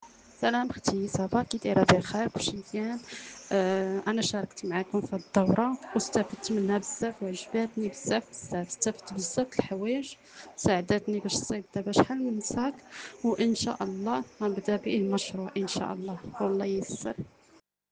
شهادات صوتية لبعض المشاركات 👇👇👇